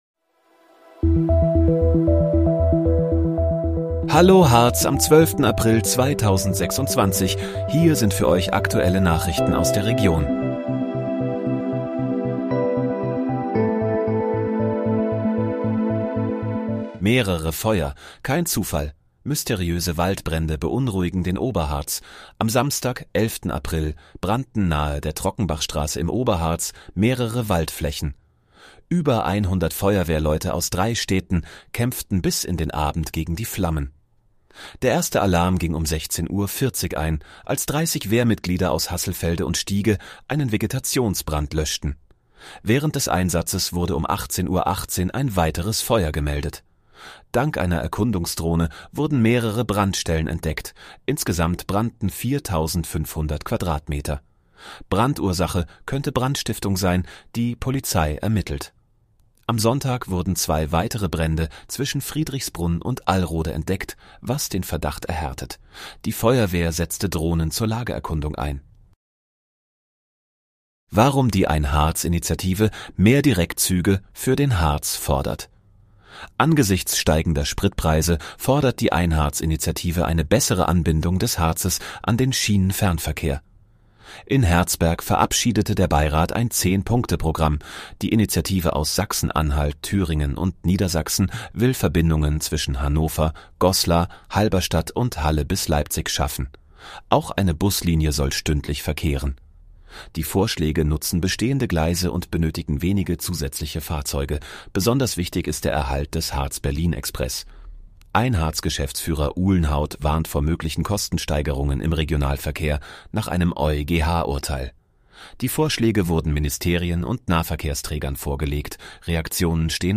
Hallo, Harz: Aktuelle Nachrichten vom 12.04.2026, erstellt mit KI-Unterstützung